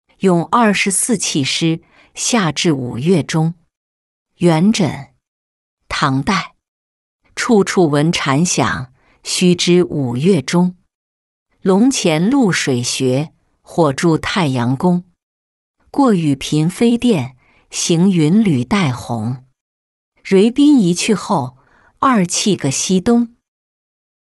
咏二十四气诗·夏至五月中-音频朗读